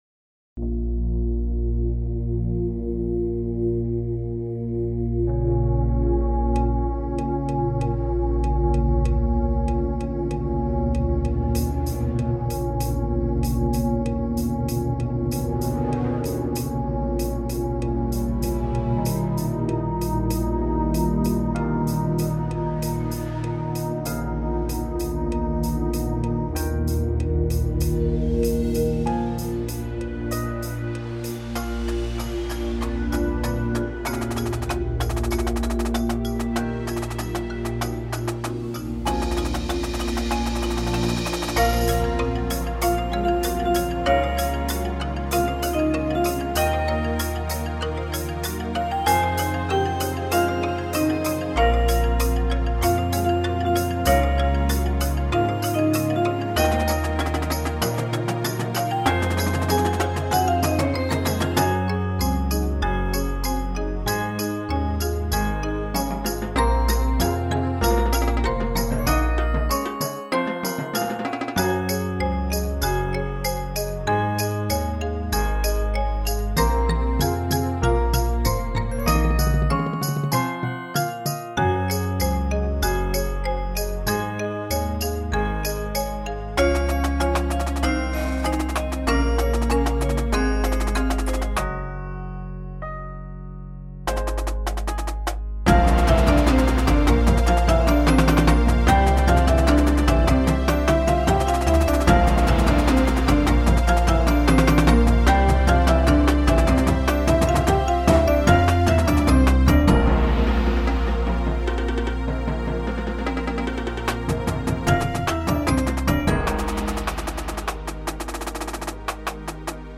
Grade Level: Jr. High (For the Beginning Drum Line)
• 2-4 Marimbas (2 and Opt. 4 mallet)
• 1-3 Vibes
• 1 Xylophone
• 1 Bell/Glock
• 1 Chime
• 2 Synth/Piano
• 1 Bass Guitar (Can also be played by synth if needed)
• Snare (mounted Ribbon Crasher/hi-hat opt.)
• Quints (mounted Cowbell opt.)
• 5 Bass Drums
• Marching Cymbals (Can be played by Aux Perc if needed)